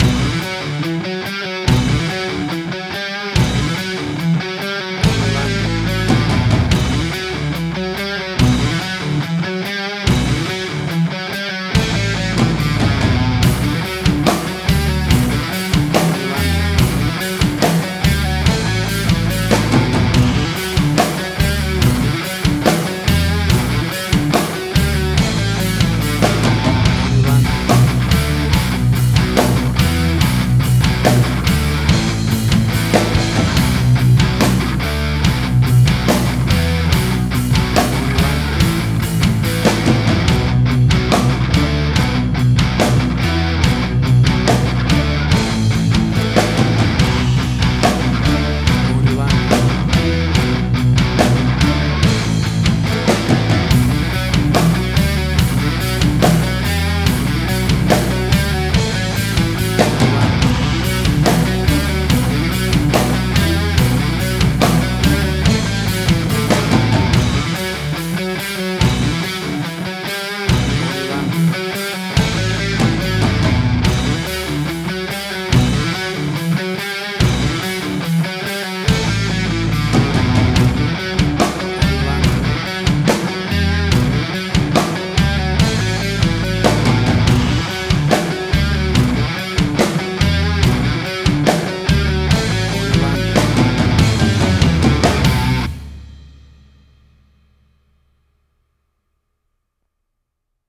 Hard Rock
Heavy Metal.
Future Retro Wave
Tempo (BPM): 72